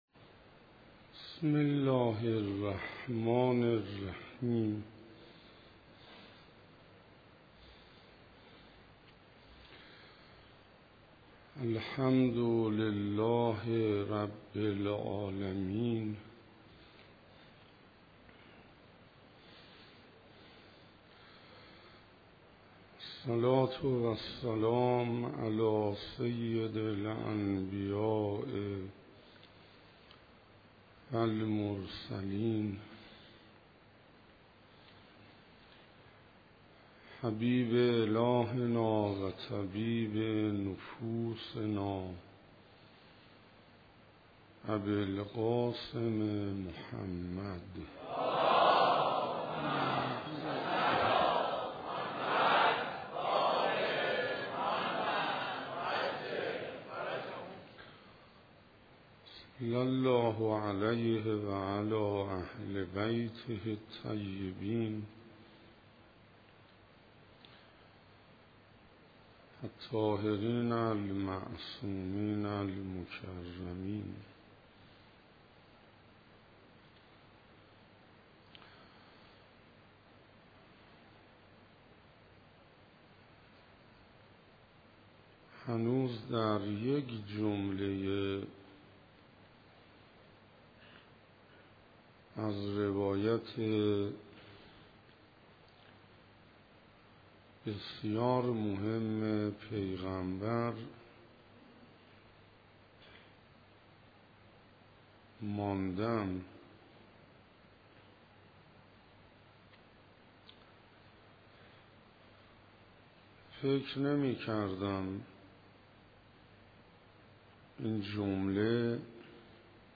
ویژگی های خودشناسان - جلسه سیزدهم _ شب 14 رمضان - رمضان 1436 - حسینیه همدانی‌ها -